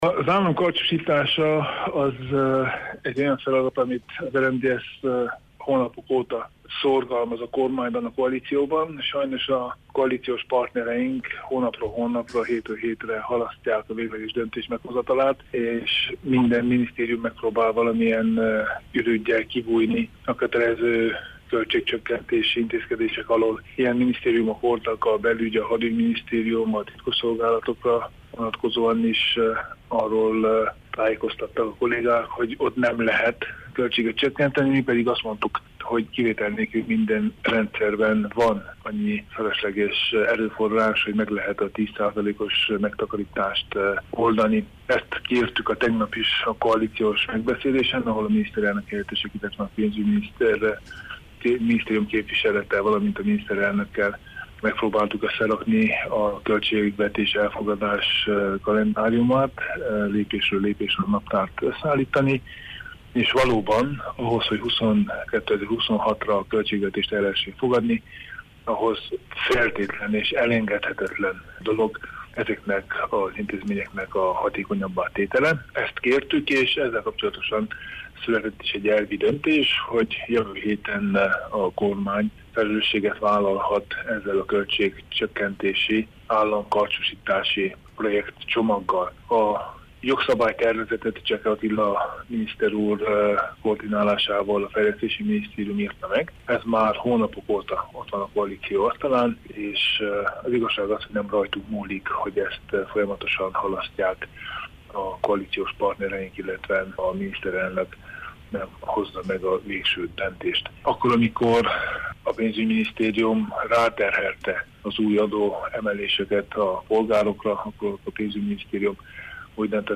A megtakarítás elsősorban a bérpótlékok mérséklésével érhető el, az alapbérekhez nem nyúlhatnak – közölte Tánczos Barna miniszterelnök-helyettes.